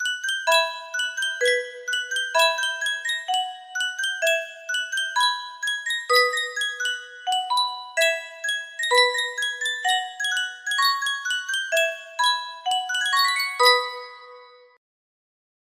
Sankyo Music Box - By the Beautiful Sea 6Q music box melody
Full range 60